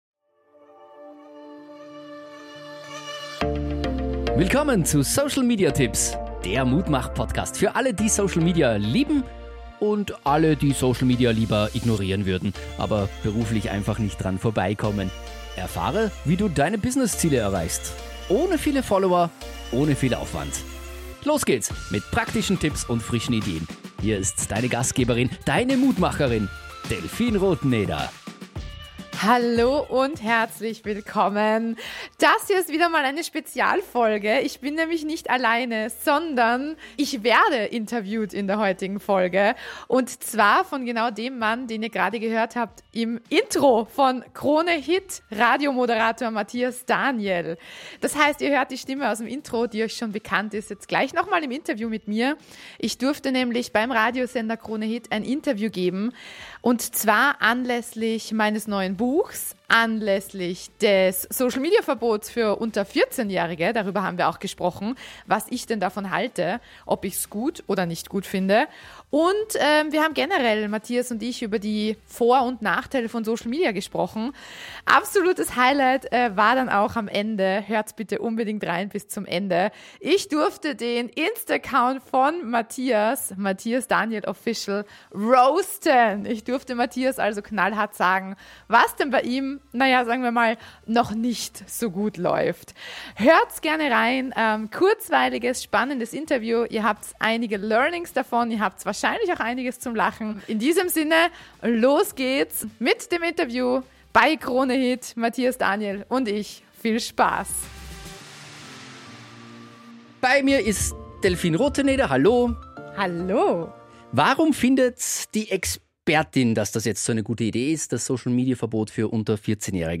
Antworten auf diese Fragen und dazu einige Learnings, sowie unterhaltsame Momente gibt's in dieser Podcast-Folge, die im Zuge meiner offiziellen Interview-Aufnahme beim Radiosender Kronehit entstanden ist.